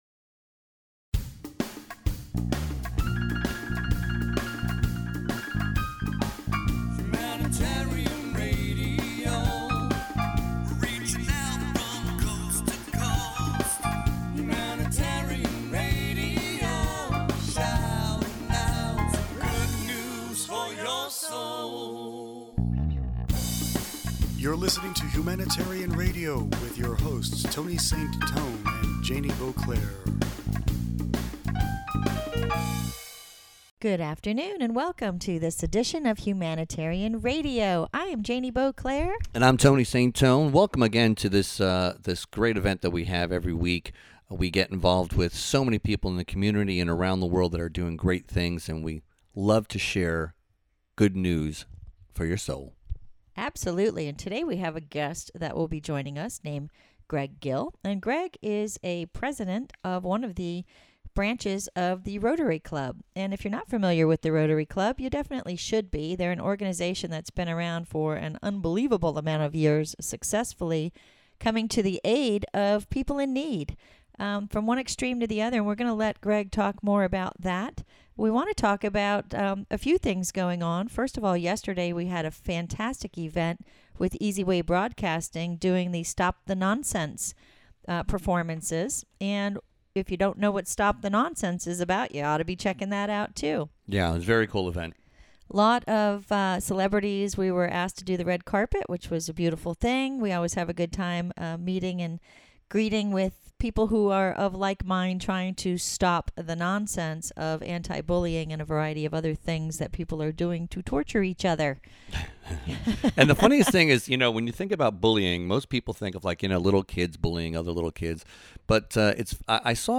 Entertainment HR Interview